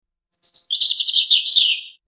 Bird Chirping 02
Bird_chirping_02.mp3